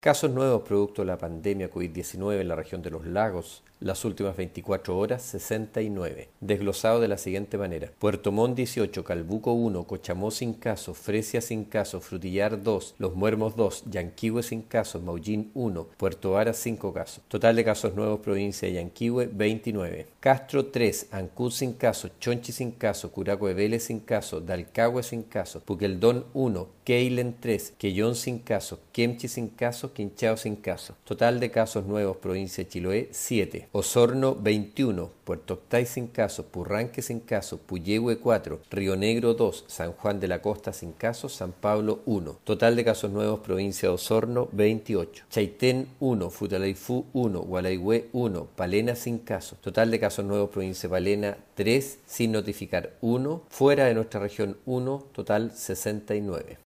El mediodía de este miércoles, el Seremi de Salud Alejandro Caroca, entregó el reporte de nuevos contagios por Covid-19 en la región de Los Lagos, con corte al día 13 de julio, a las 18 hrs: